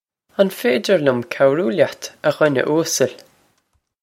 Pronunciation for how to say
On fay-dir lyum kow-roo lyat, ah ghwin-eh oos-el?
This is an approximate phonetic pronunciation of the phrase.